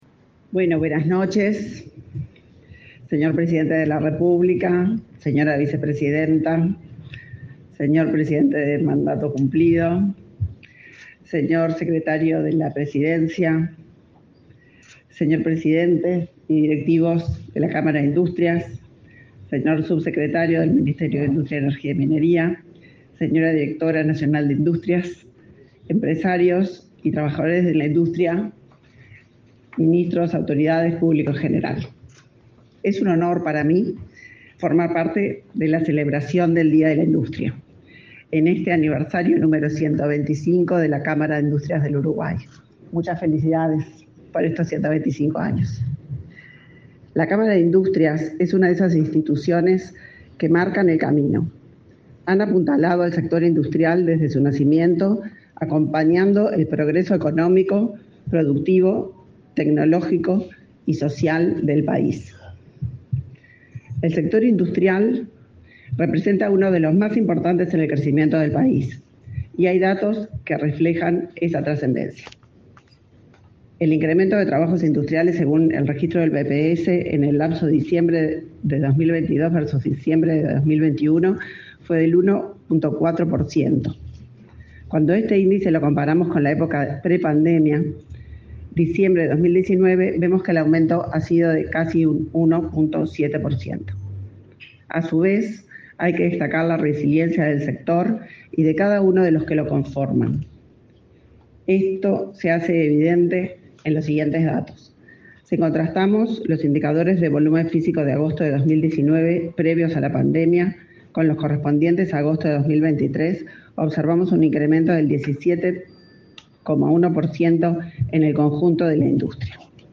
Palabras de la ministra de Industria, Energía y Minería, Elisa Facio
Con la presencia del presidente de la República, Luis Lacalle Pou, se realizó, este 14 de noviembre, el acto por el Día de la Industria y 125.°
La ministra de Industria, Energía y Minería, Elisa Facio, participó en la oratoria.